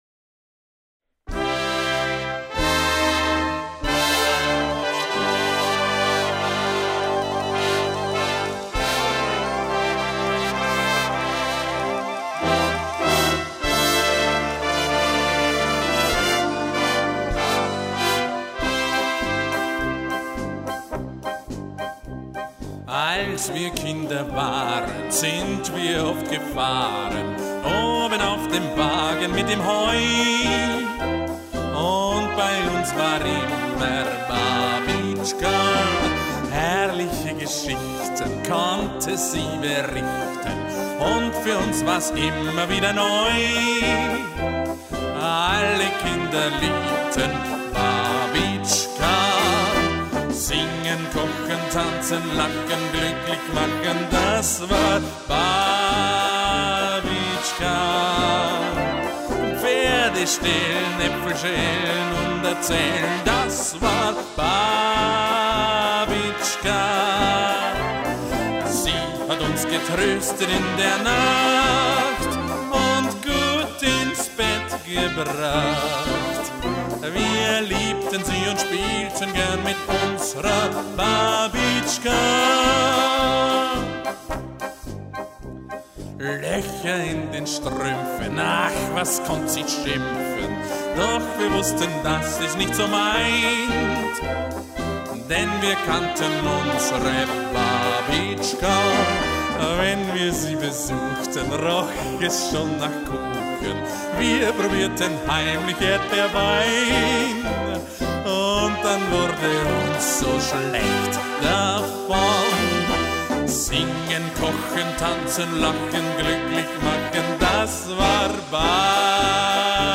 Medley
Medley mit Gesang